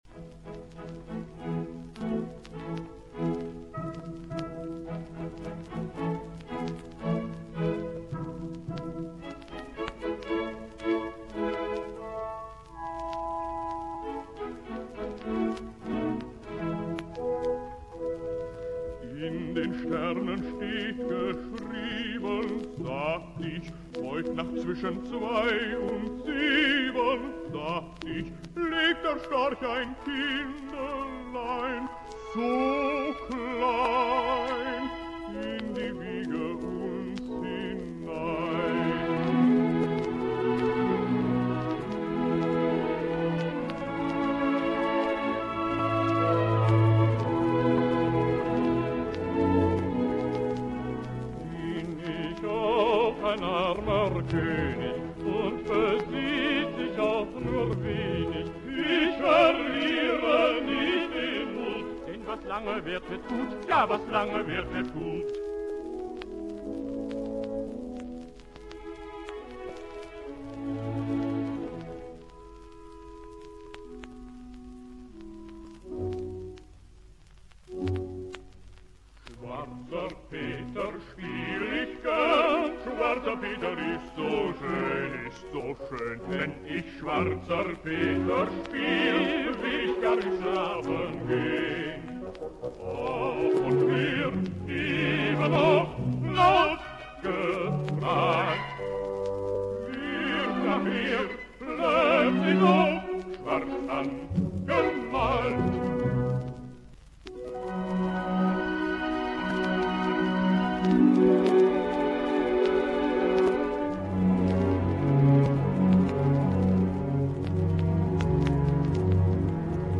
a children's opera
Medley
(all much abridged)